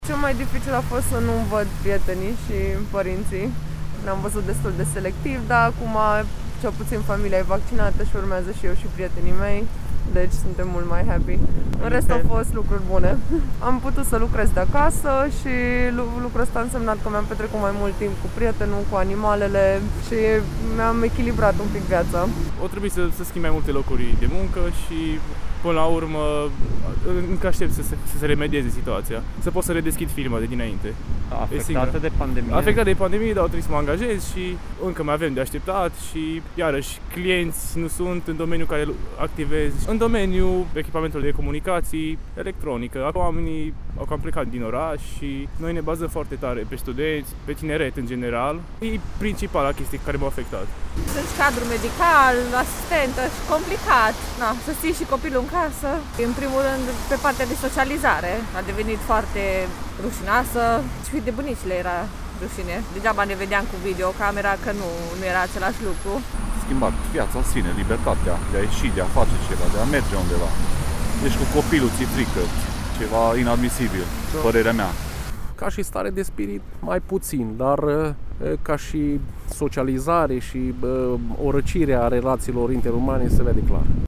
Am întrebat și mai mulți clujeni ce le-a fost cel mai greu în pandemie, îi puteți asculta mai jos:
Voxuri-dificultati-stare-de-urgenta.mp3